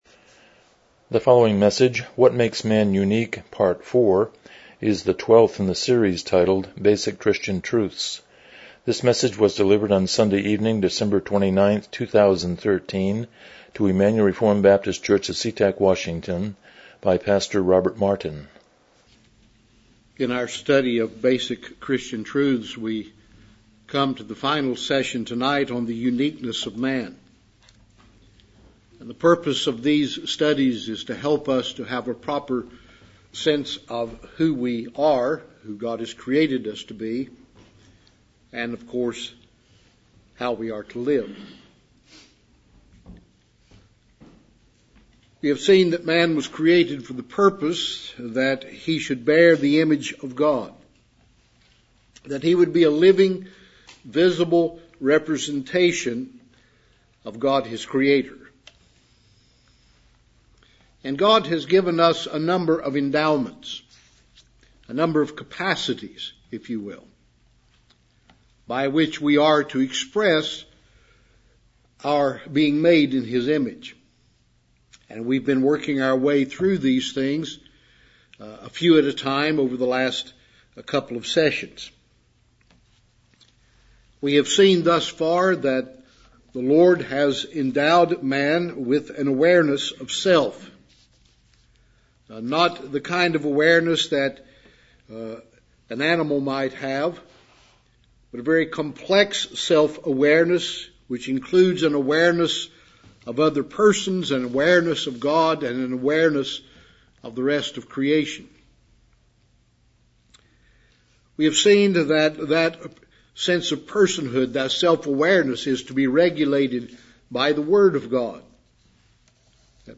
Basic Christian Truths Service Type: Evening Worship « New Years Resolutions